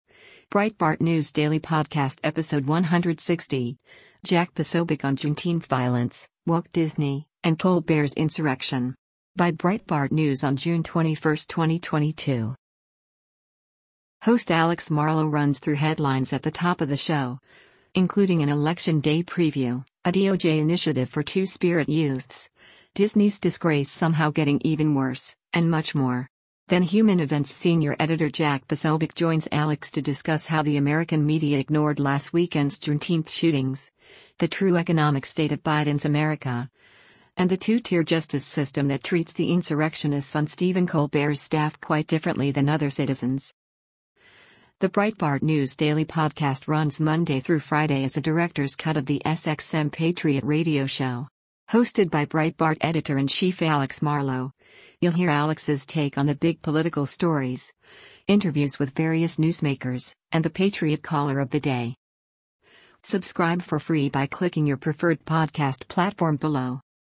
Then Human Events Senior Editor Jack Posobiec joins Alex to discuss how the American media ignored last weekend’s Juneteenth shootings, the true economic state of Biden’s America, and the two-tier justice system that treats the insurrectionists on Stephen Colbert’s staff quite differently than other citizens.
The Breitbart News Daily Podcast runs Monday through Friday as a “Director’s Cut” of the SXM Patriot radio show. Hosted by Breitbart Editor-in-Chief Alex Marlow, you’ll hear Alex’s take on the big political stories, interviews with various newsmakers, and the Patriot “Caller of the Day.”